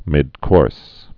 (mĭdkôrs)